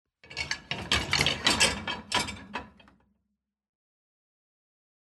Звуки посудомоечной машины
Наполняем отделение для посуды